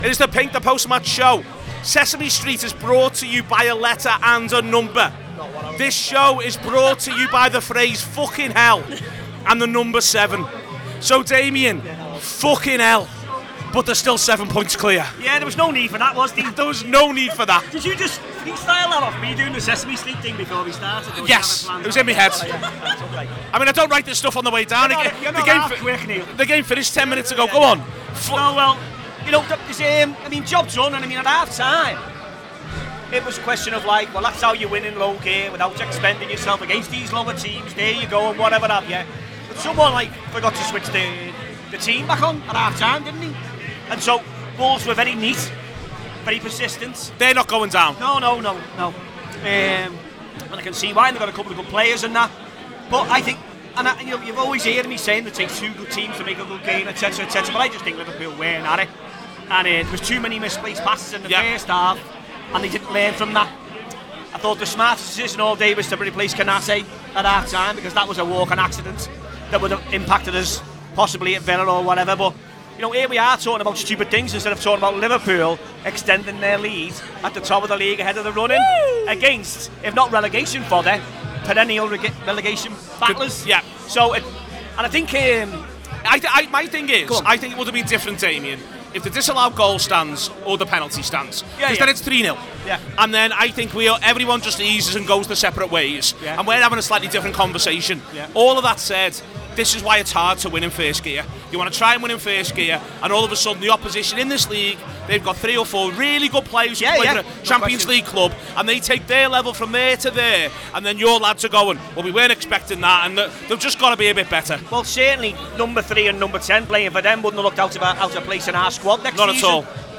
Below is a clip from the show – subscribe to The Anfield Wrap for more reaction to Liverpool 2 Wolverhampton Wanderers 1…